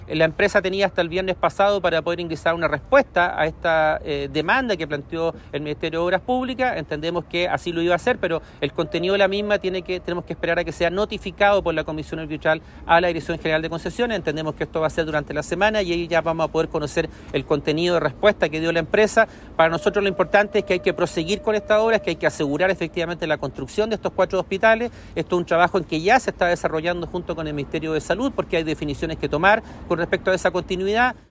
El seremi de Obras Públicas, Hugo Cautivo, señaló que esta semana se debería conocer la respuesta que entregó la empresa.